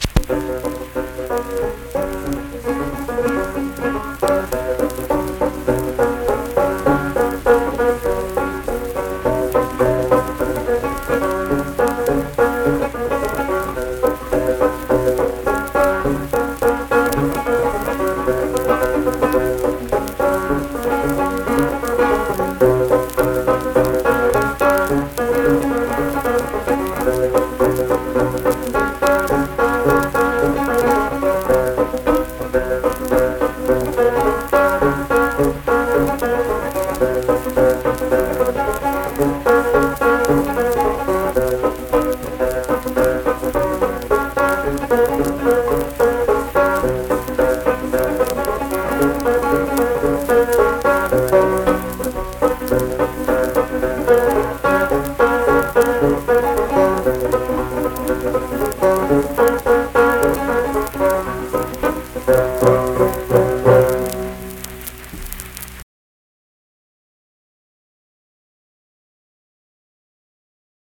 (Banjo Tune)
Unaccompanied vocal and banjo music
Instrumental Music
Banjo
Clay County (W. Va.), Clay (W. Va.)